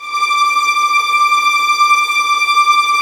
Index of /90_sSampleCDs/Roland L-CD702/VOL-1/STR_Vlns Tremelo/STR_Vls Trem wh%